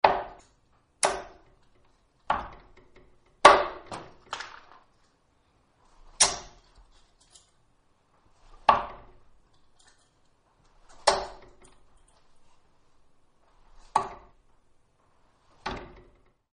Звуки дартса
Кто-то в баре метает дротики